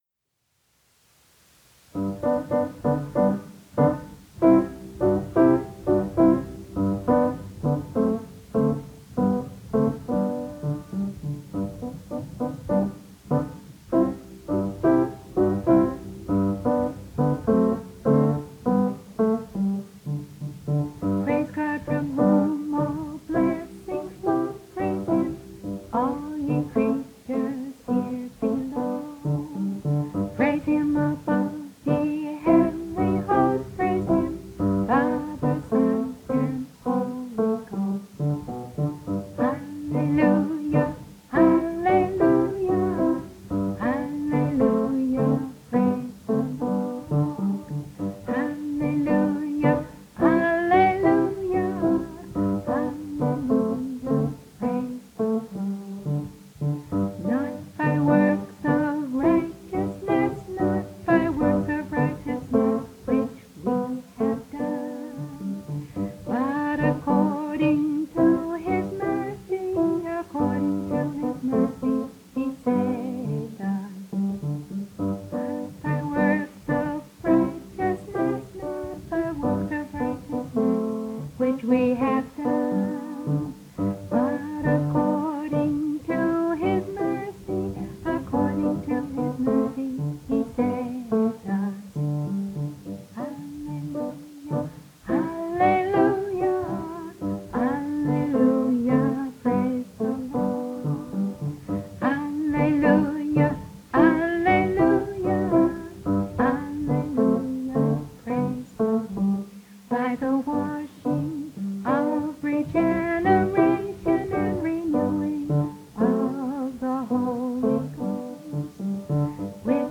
Doxology Doo-wop